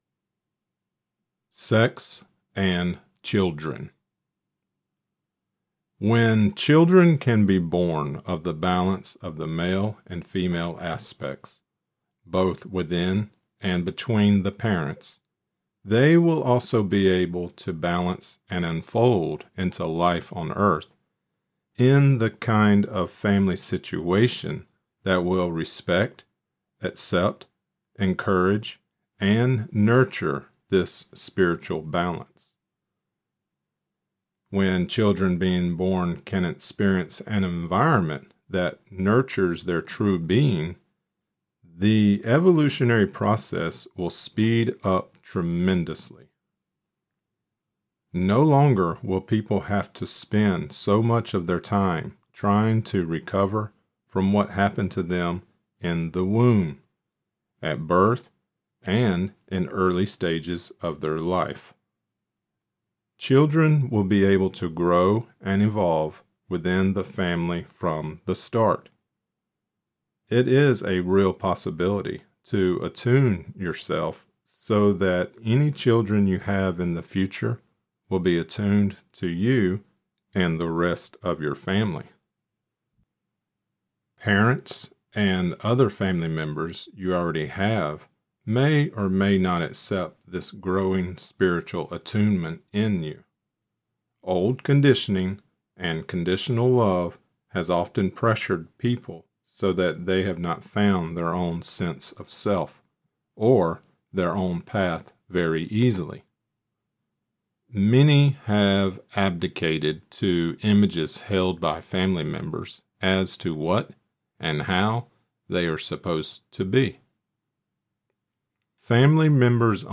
This is part 13 of the Right Use of Will and this lecture includes: Sex and Children